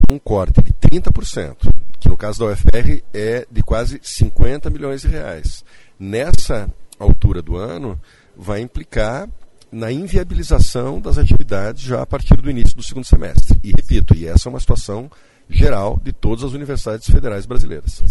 A explanação do reitor da UFPR foi na tribuna da Assembleia Legislativa do Paraná